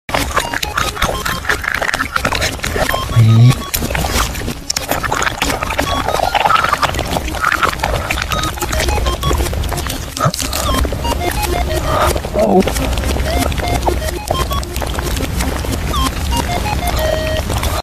Random Emoji Food Mukbang Asmr Sound Effects Free Download